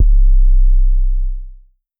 808 (Jumpin).wav